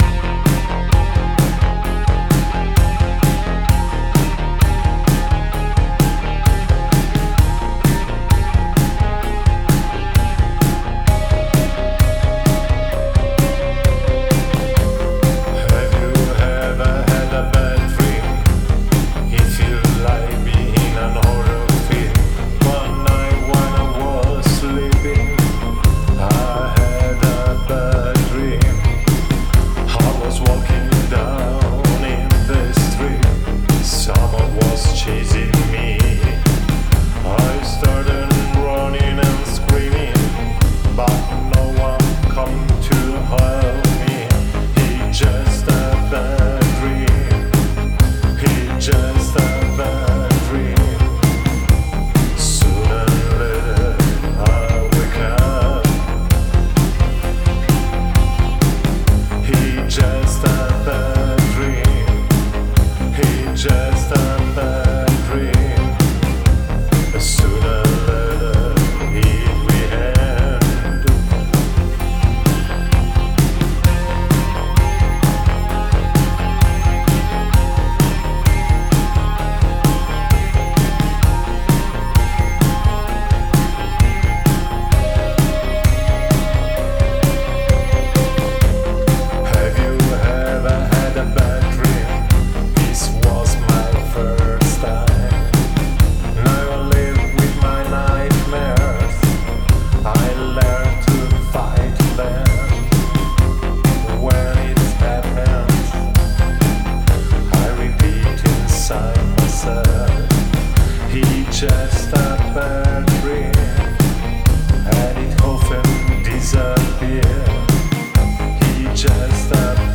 darkwave
suoni eterei, synth malinconici e atmosfere oscure